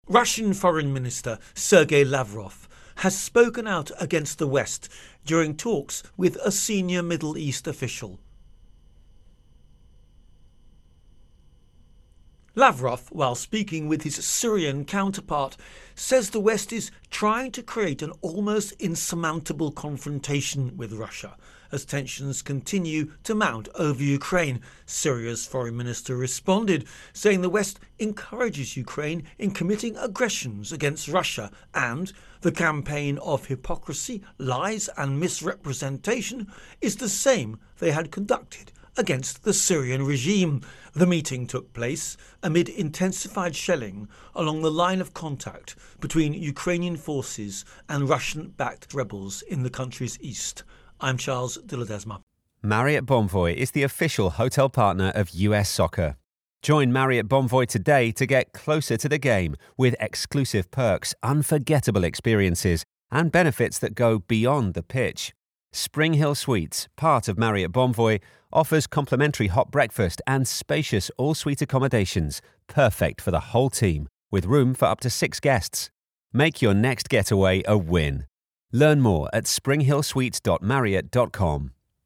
Ukraine-Tensions-Russia Intro and Voicer